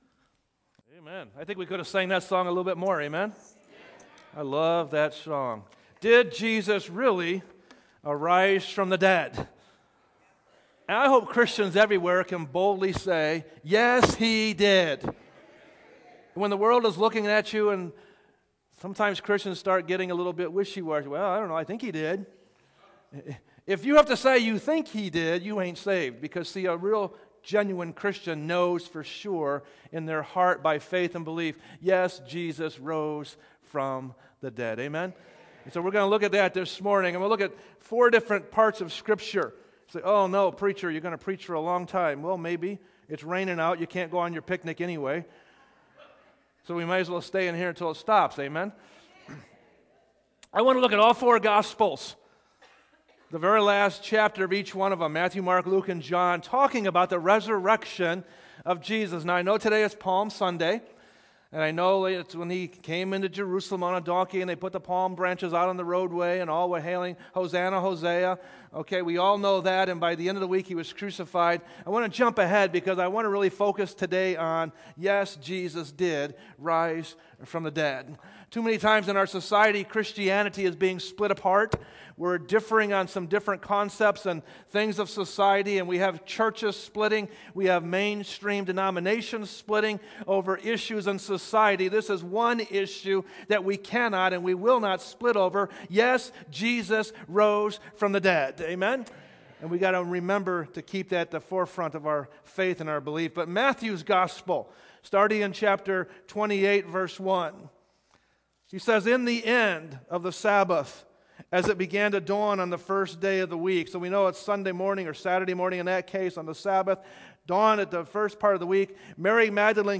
sermons preached at Grace Baptist Church in Portage, IN